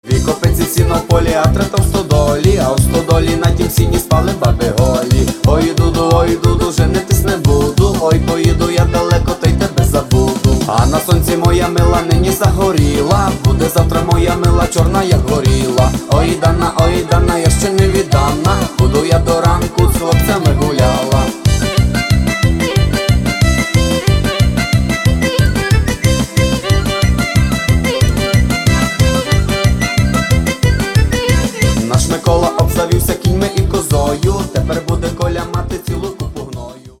Каталог -> MP3-CD -> Народная